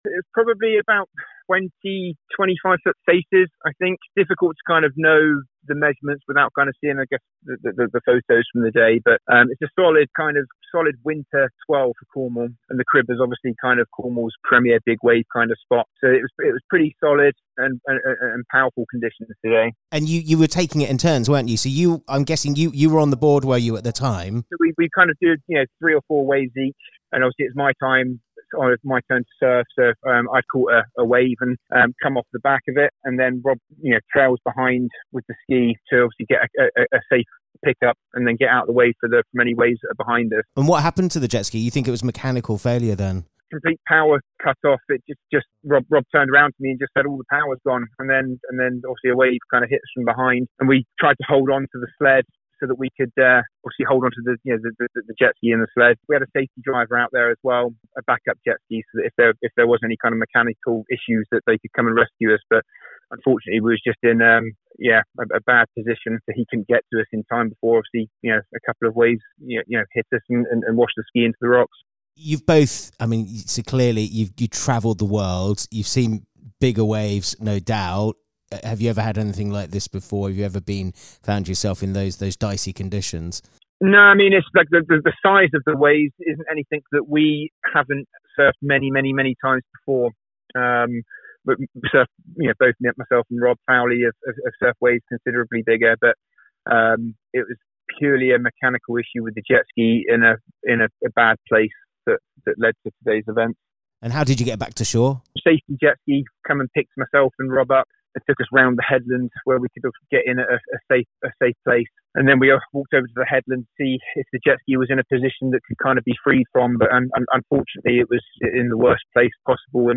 Speaking to Radio Newquay